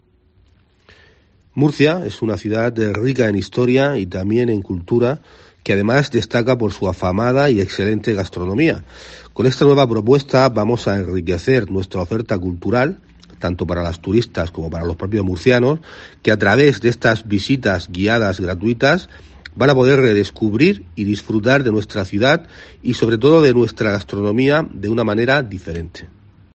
Pedro García Rex, concejal de Cultura, Turismo y Deportes del Ayuntamiento de Murcia